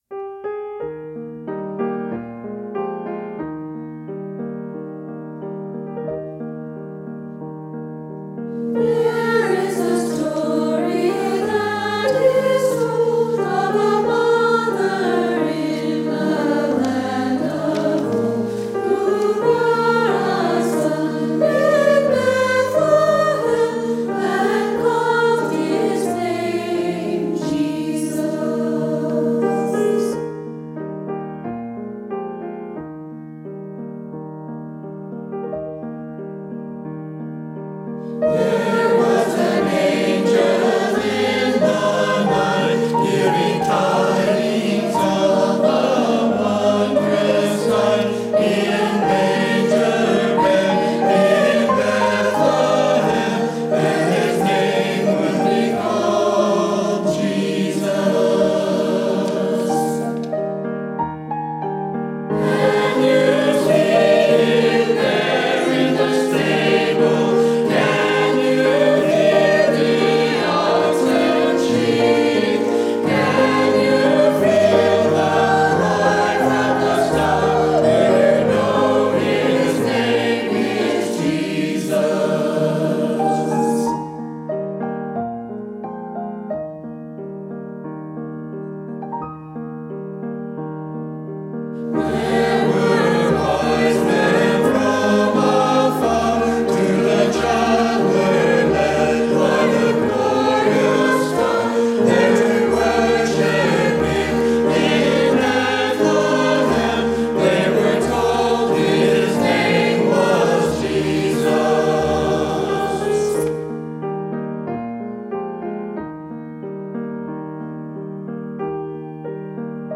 Mostly four-part singing with music-box style accompaniment.